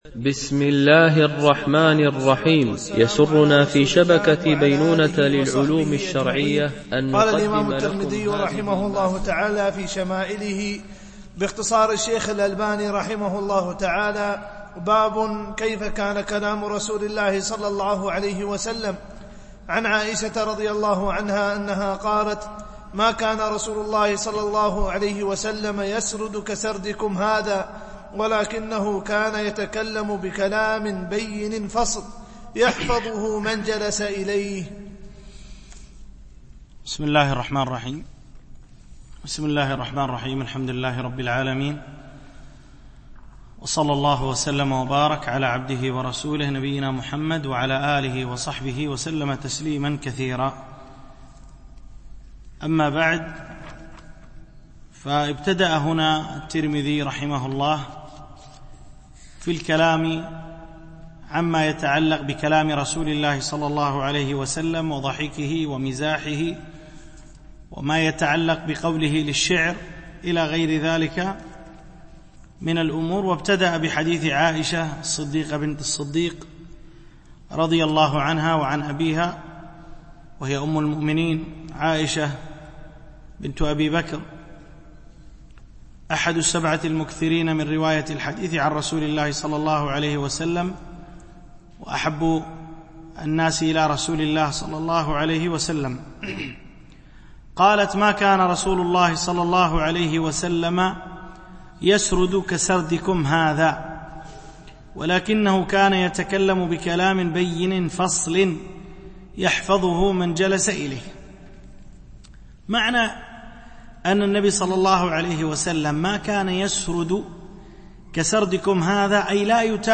الدرس20